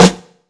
kits/RZA/Snares/GVD_snr (32).wav at main
GVD_snr (32).wav